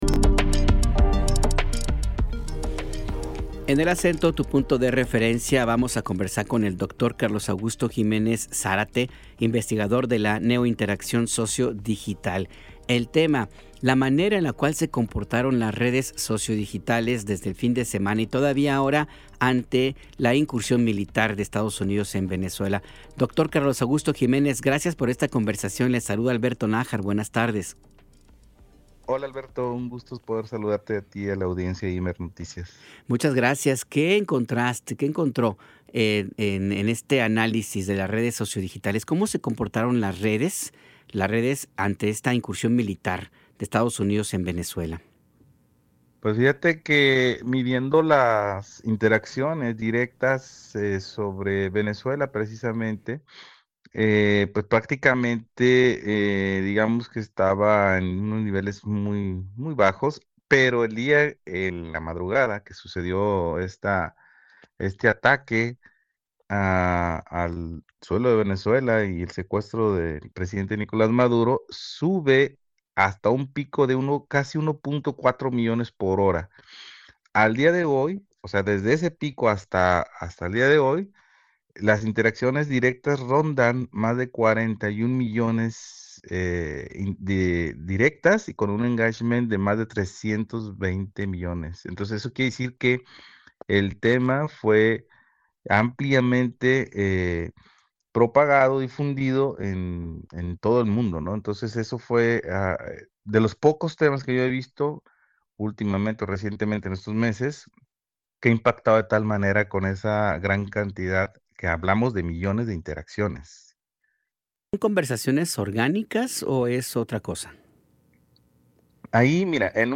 En entrevista, Juan Rubio Gualito, presidente de la Comisión de Asuntos Laborales, Trabajo y Previsión Social del Congreso de la Ciudad de México, explica la iniciativa del trabajo a distancia en situaciones de contingencia ambiental.